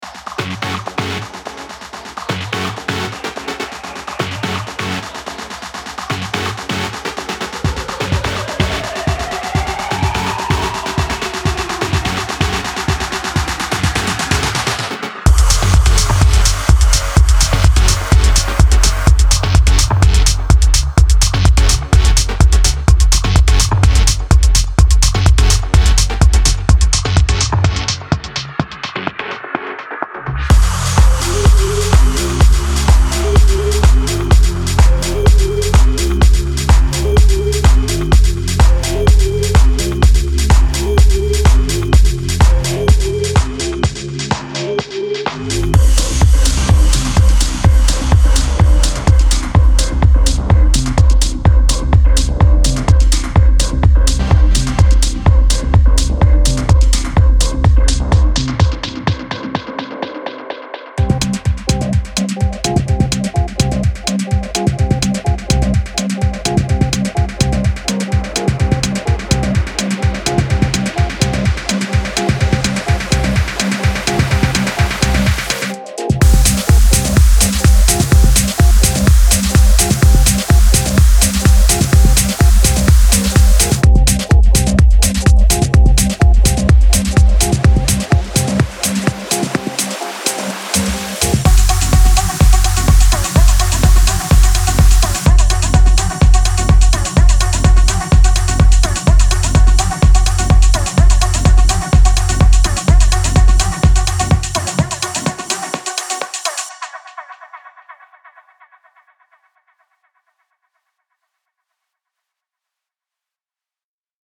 包括tr，EDM，音乐节和硬式踢。
50为KICK 2精心制作的Future Bass滴答声和预设
50为踢2精心制作的大房间和节日点击和预设
50为KICK 2精心制作的Techno咔嗒声和预设